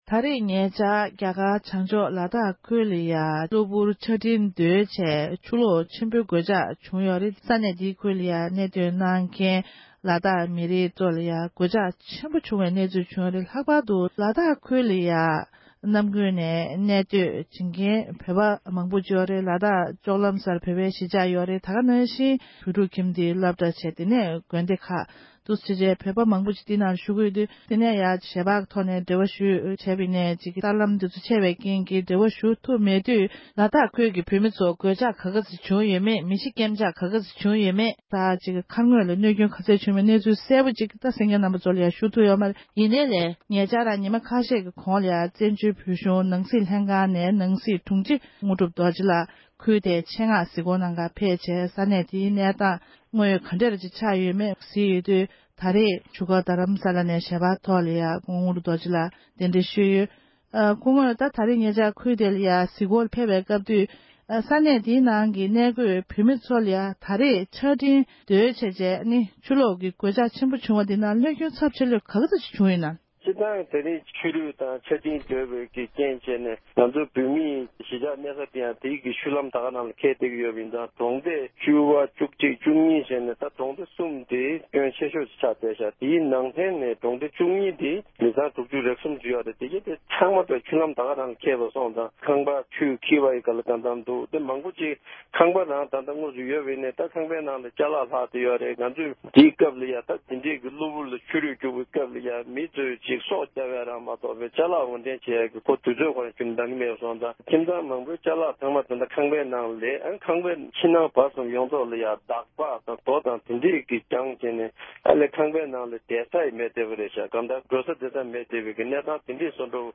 དྲུང་ཆེ་མཆོག་ལ་འབྲེལ་ཡོད་གནས་ཚུལ་བཀའ་འདྲི་ཞུས་པར་གསན་རོགས༎